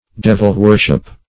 Meaning of devil-worship. devil-worship synonyms, pronunciation, spelling and more from Free Dictionary.
devil-worship.mp3